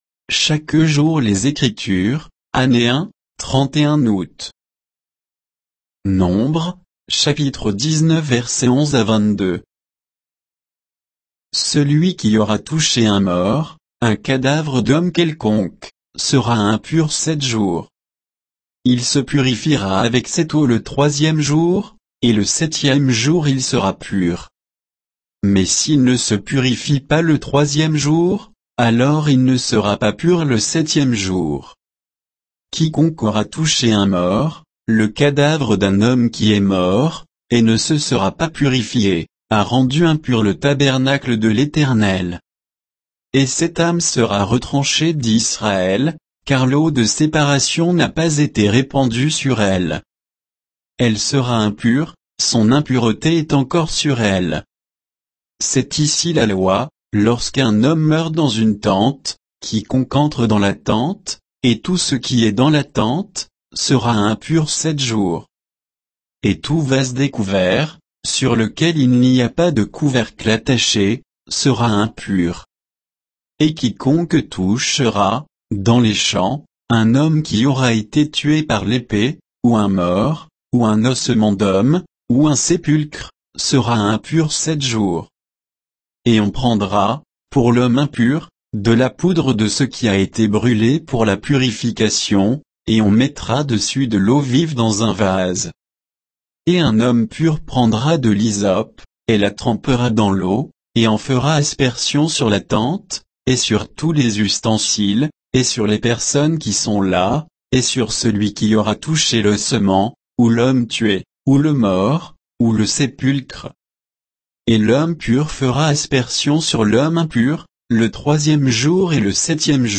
Méditation quoditienne de Chaque jour les Écritures sur Nombres 19